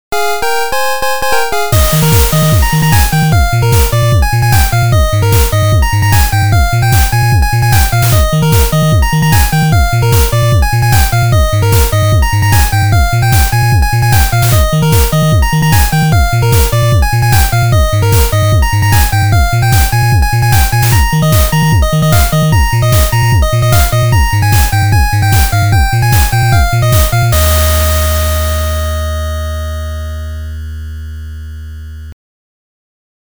ファミコン風アレンジは挑戦のひとつということで。
VSTiにmagical8bitとファミシンセで。